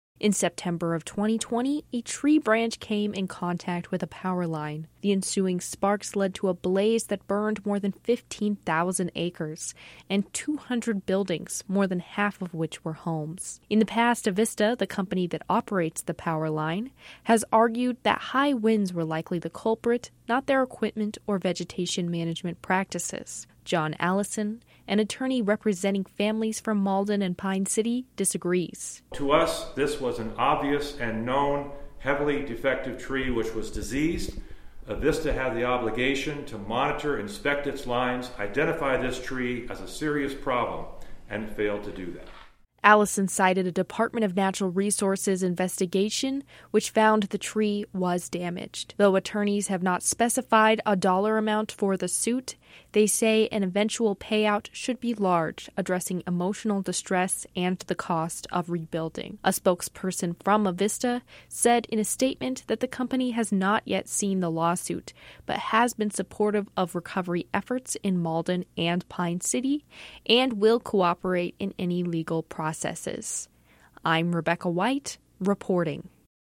reports on a lawsuit filed by families from Malden and Pine City, where a 2020 wildfire caused massive destruction